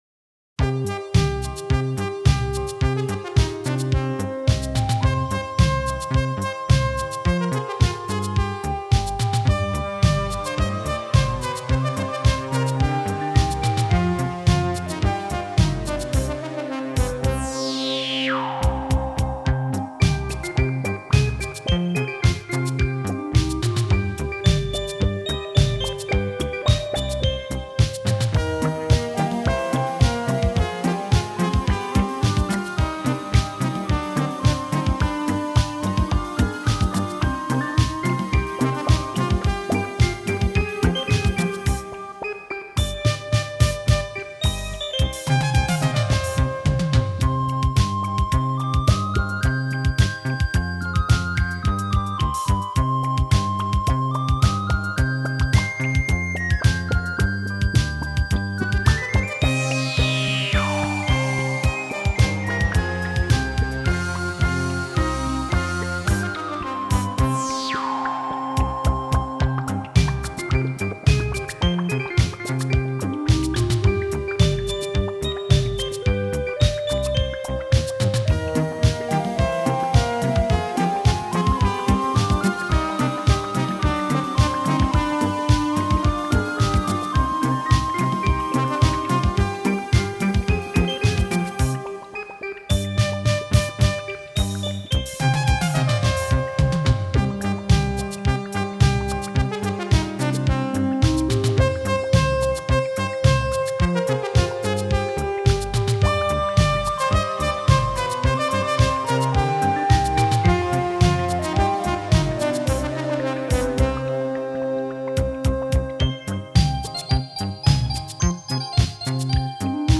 电子合成乐器